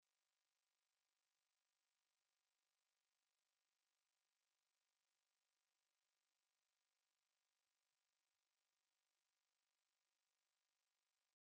Shure SM7b brummt GoXLR / Focusrite scarlett gen3
Hallo, ich habe das Problem das mein Shure SM7b brummt.